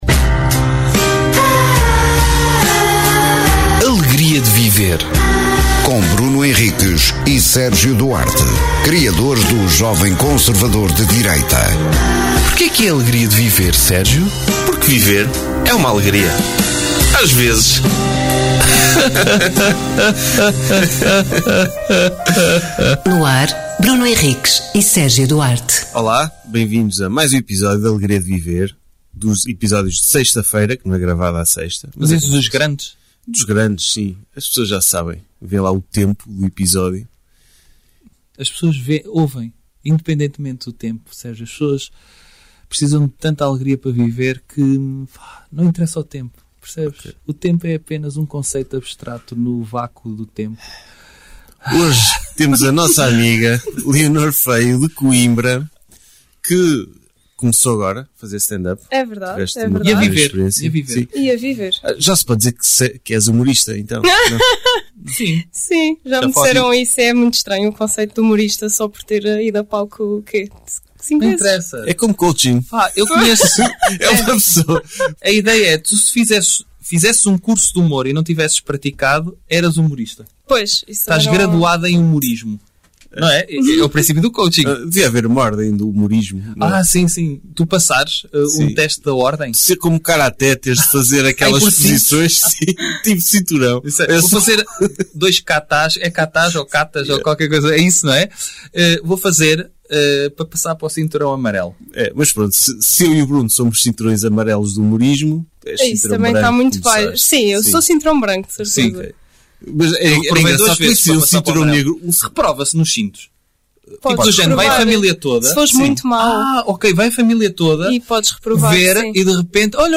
Sem preparação ou discussão prévia, abrem o microfone e partem em cada episódio para um tema desconhecido; que exploram com humor e sentido crítico… Uma hora transmitida em direto e sem filtros, que não poucas vezes terá convidados, estejam no estúdio ou fora dele…